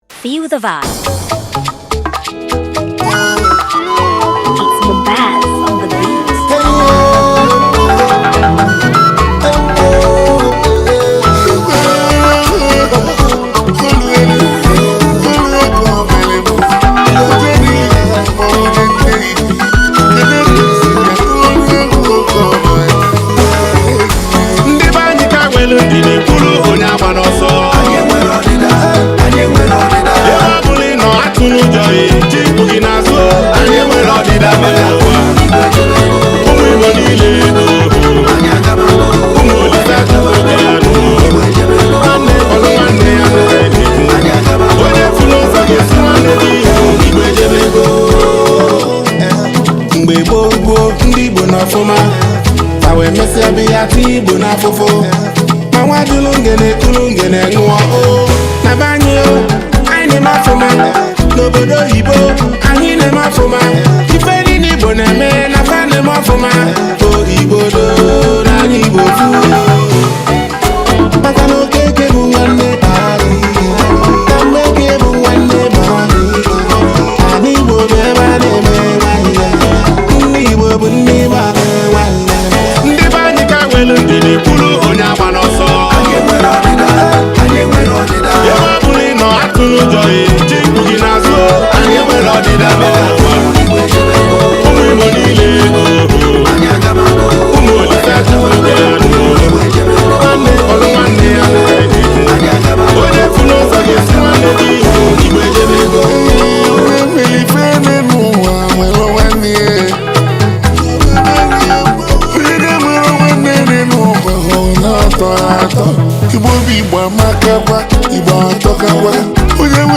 Home » Album » Bongo » Highlife » Ogene
highlife track
a good highlife tune